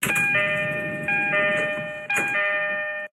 1200DoorOpen.ogg